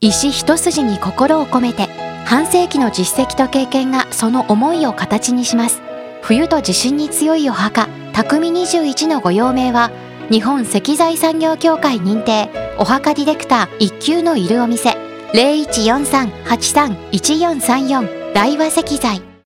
ラジオcm.mp3